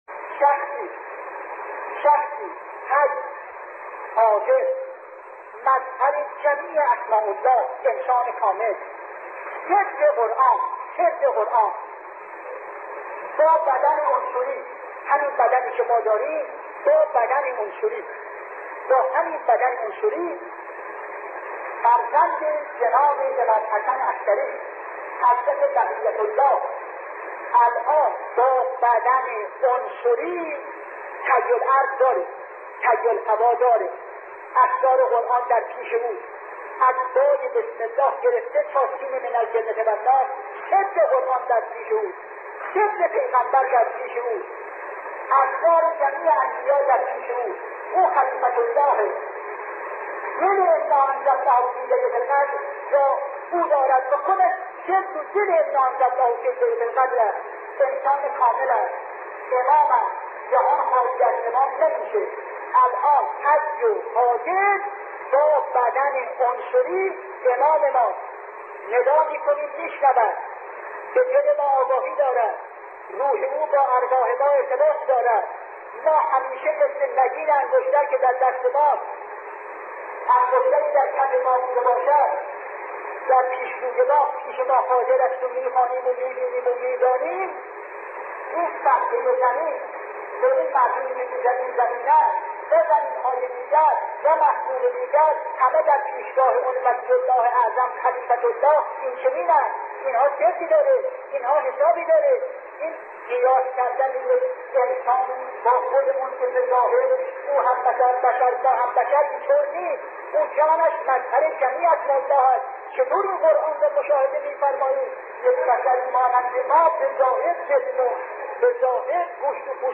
عقیق: بیاناتی از مرحوم علامه حسن زاده آملی در مورد شخصیت امام زمان ارواحنا فداه تقدیم شما فرهیختگان می شود.